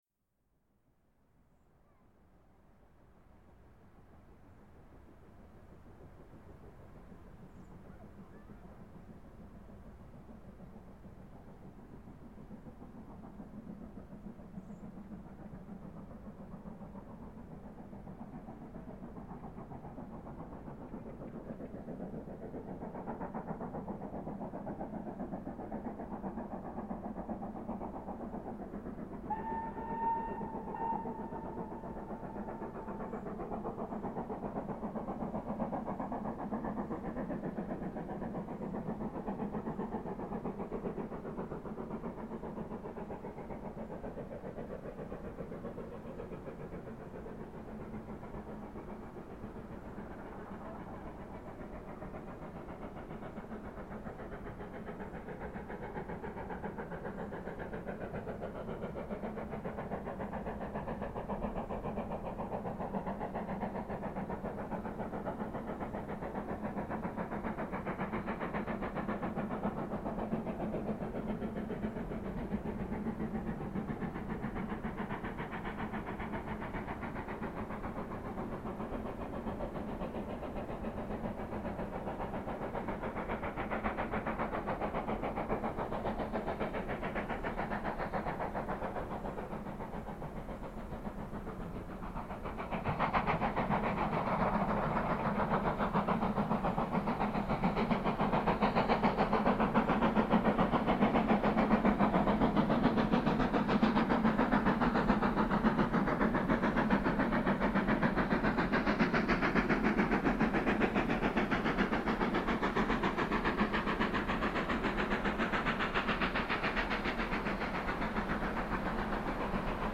80135 (BR-standard-Tenderlok) schleppt den 7-Wagen-Zug bergauf, bei Green End am 04.08.2000 um 11:55h.
80135 mit Zug nach Pickering, aufgenommen direkt hinter der Feldweg-Brücke bei Green End, um 11:55h am 04.08.2000.   Hier anhören: